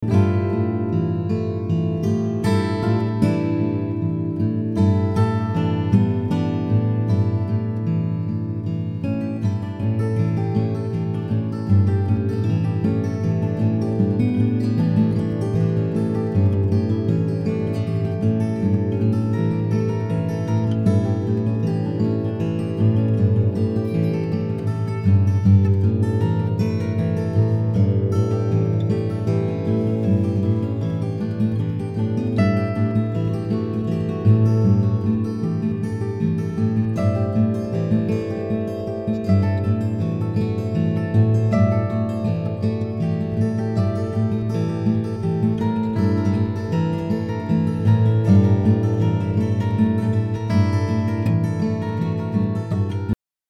duets for two acoustic guitars
guitar